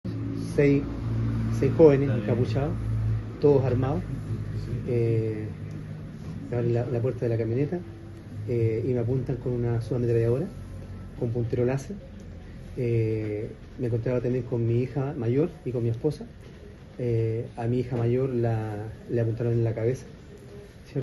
“Me apuntan con una subametralladora con puntero láser… A mi hija mayor la apuntaron en la cabeza”, contó el jefe de hogar.